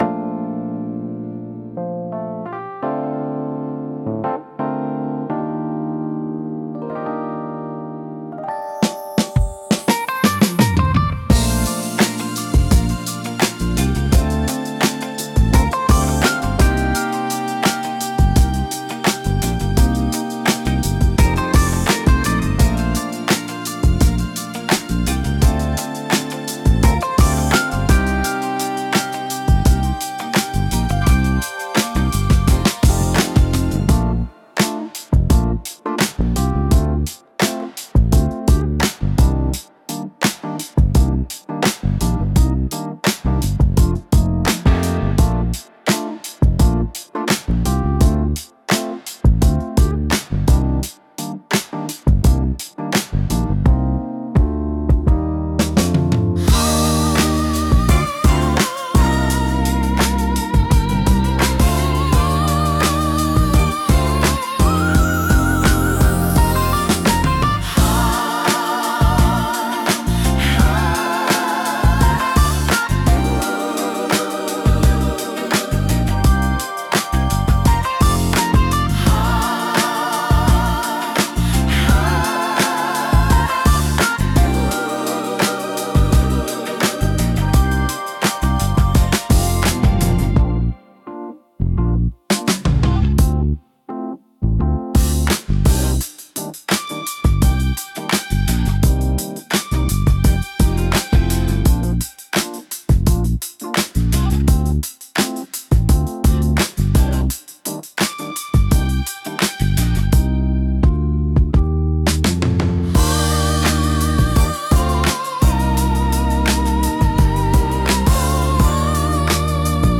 リズムの重厚感とグルーヴ感、感情豊かなボーカルが特徴で、深みと熱量のあるサウンドが魅力です。